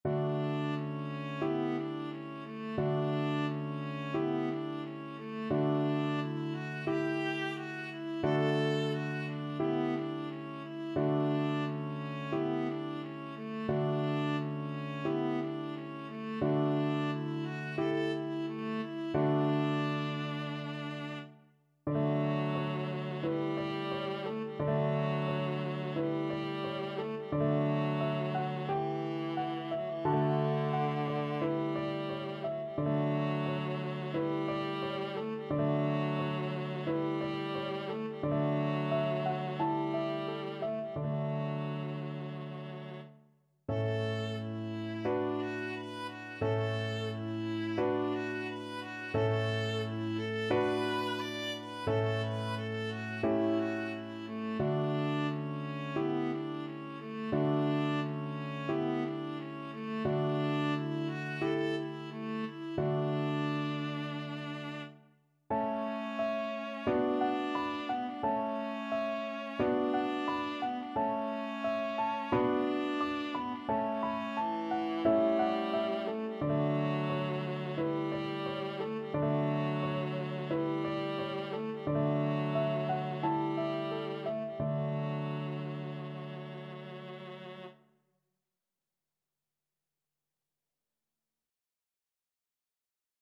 Viola
D major (Sounding Pitch) (View more D major Music for Viola )
Moderato = c. 88
Classical (View more Classical Viola Music)
gounod_angelus_VLA.mp3